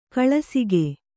♪ kaḷasige